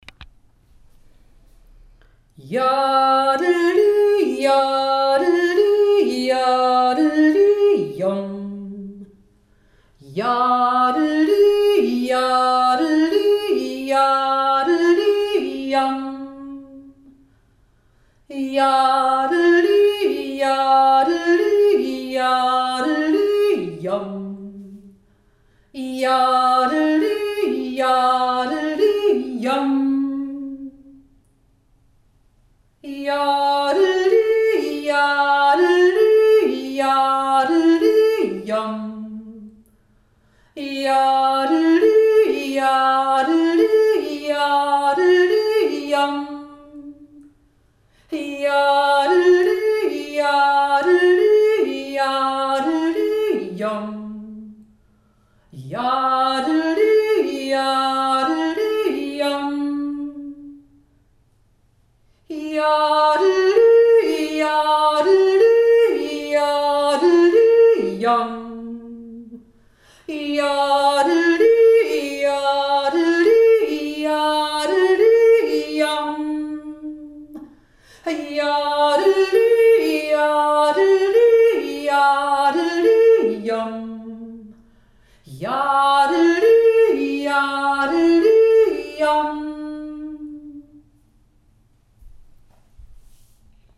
Jodler aus Vorarlberg
1. Stimme